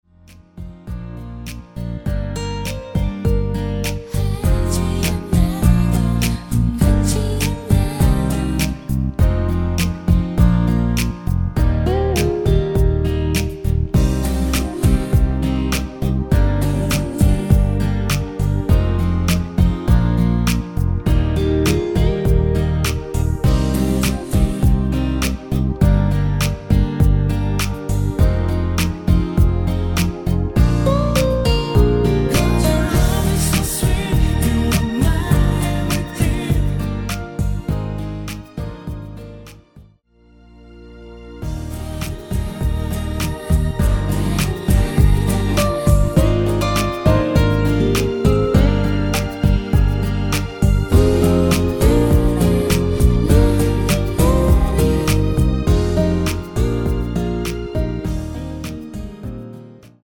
코러스만 포함된 MR 입니다.(미리듣기 참조)
Eb
앞부분30초, 뒷부분30초씩 편집해서 올려 드리고 있습니다.